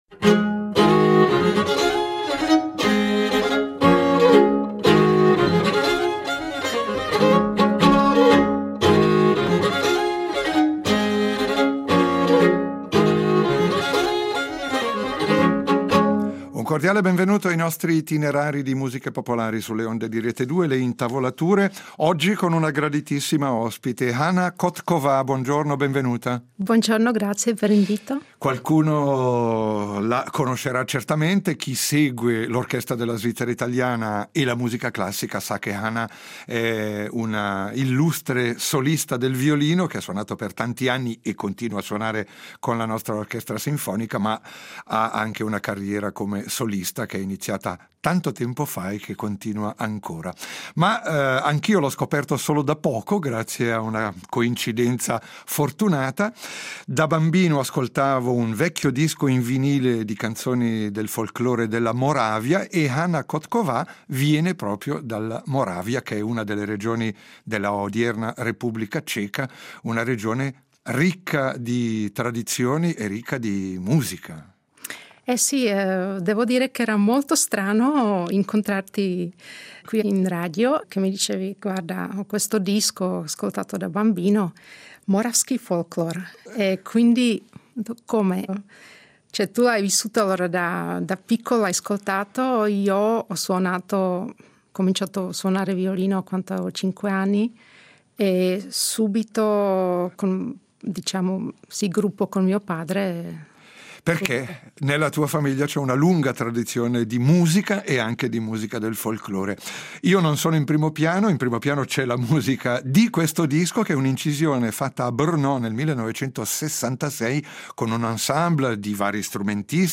Nei percorsi di questa e della prossima settimana vi proponiamo un’incursione in un repertorio poco conosciuto dalle nostre parti: il folklore della Moravia , regione orientale dell’odierna Repubblica Ceca, con un ricco patrimonio musicale e terra di compositori quali Janáček , Korngold e Mahler. Lo facciamo a partire da una registrazione storica della Radio di Brno, che nel 1966 aveva invitato un ensemble tipico ad interpretare canti e danze della tradizione: violini, clarinetto, cimbalom e varie voci per un repertorio pieno di ritmo e di melodia, espressione di un mondo prevalentemente rurale, a volte con malinconia ma spesso con tanta gioia di vivere!